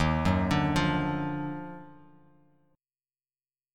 EbmM9 Chord
Listen to EbmM9 strummed